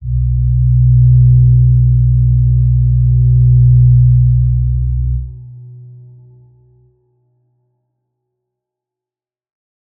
G_Crystal-B2-f.wav